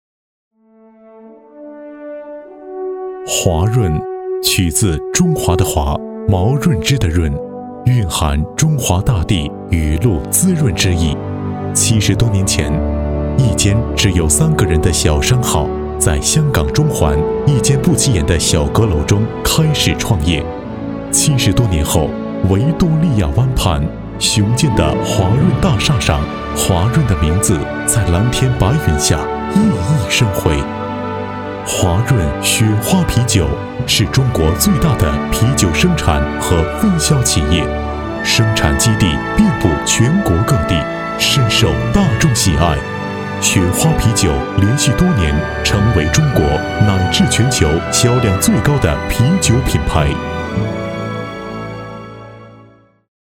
宣传片配音作品在线试听-优音配音网
男声配音